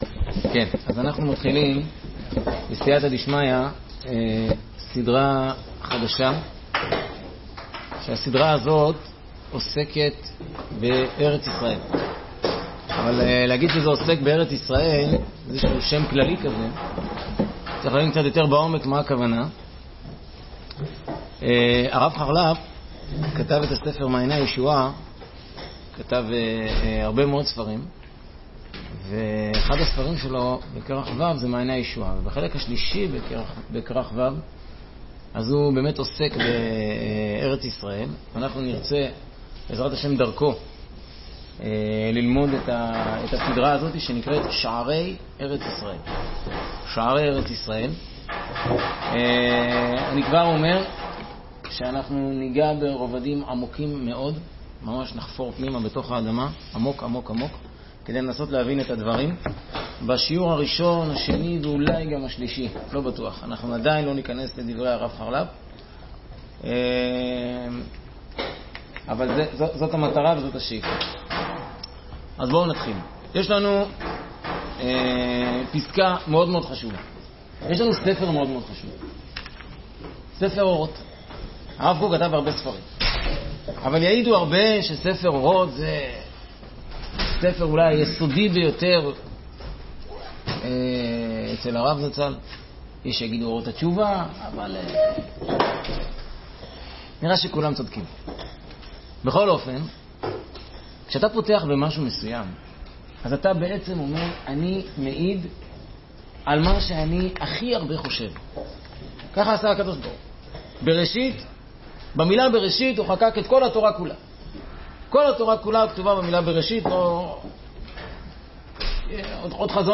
"תפילה לאל חיי" | שיעור 13